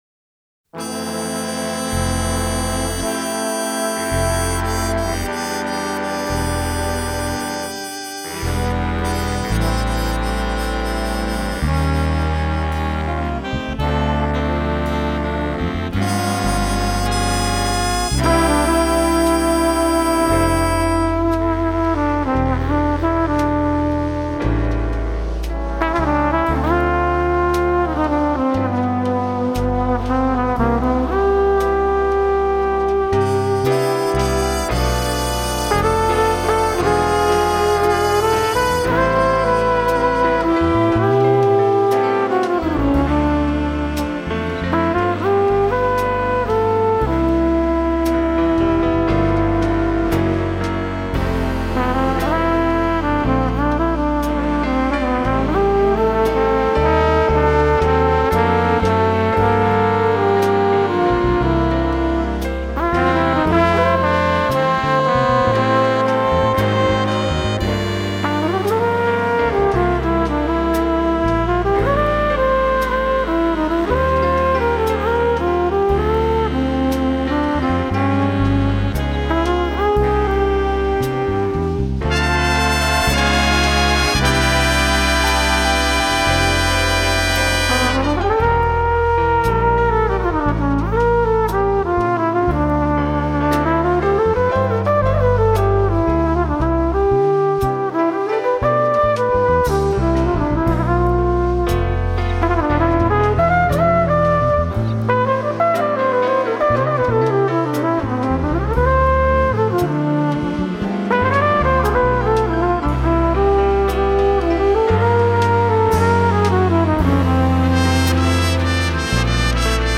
MUSIC FOR BIG BAND